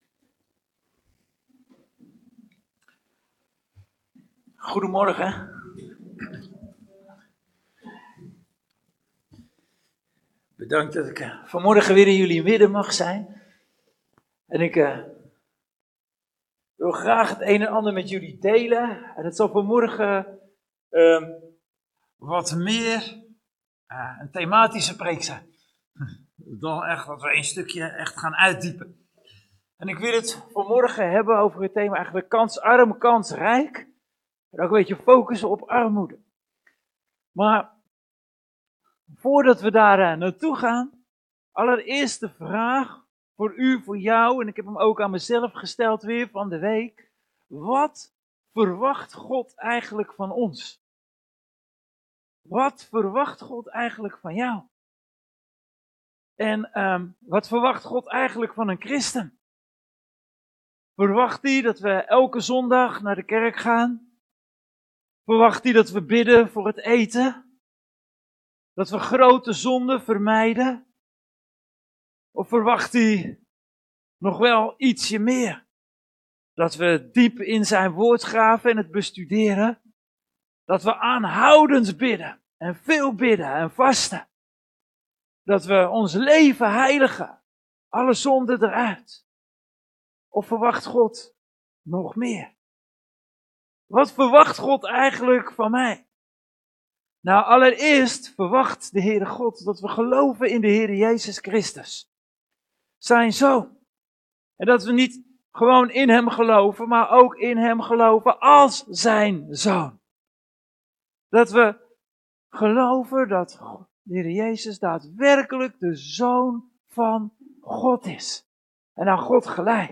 Geplaatst in Preken